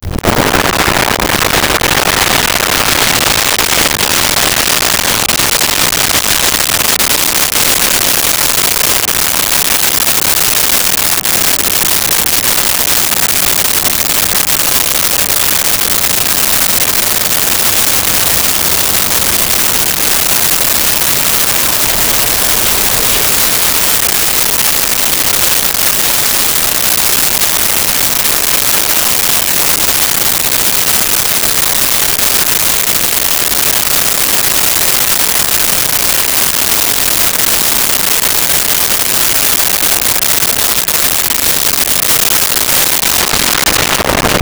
18 Wheeler In Idle Stop
18 Wheeler In Idle Stop.wav